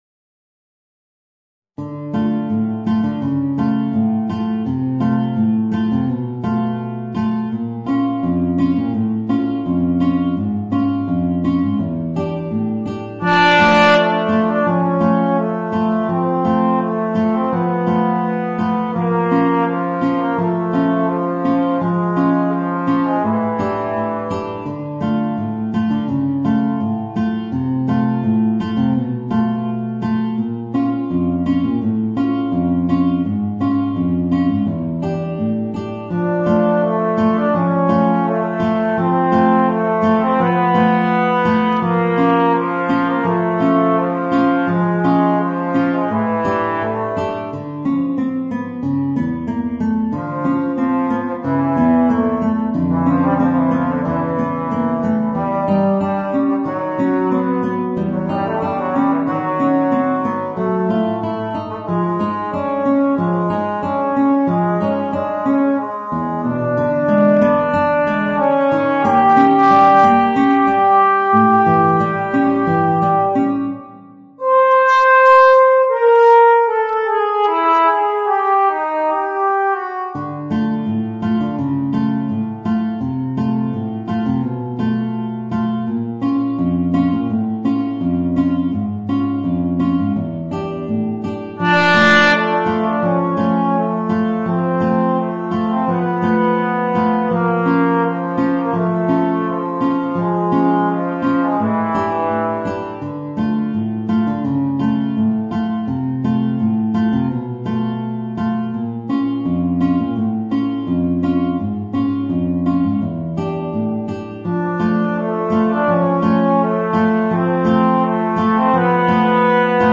Voicing: Alto Trombone and Guitar